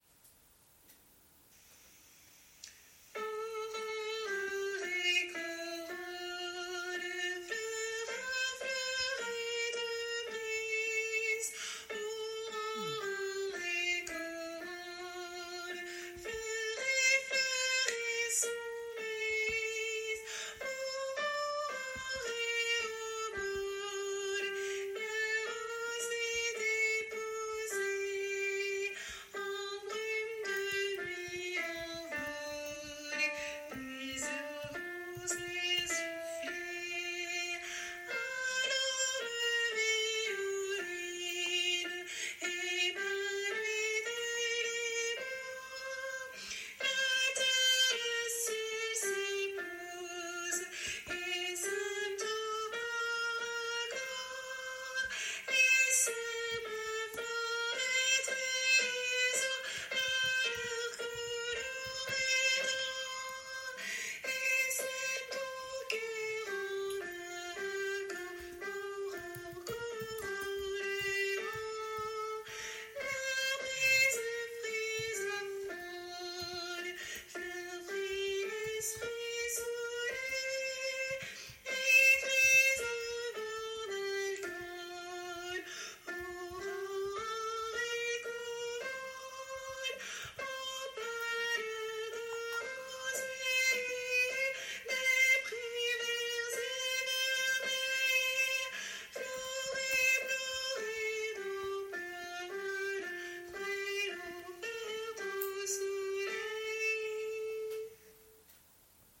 - Oeuvre pour choeur à 4 voix mixtes (SATB)
MP3 versions chantées
Ténor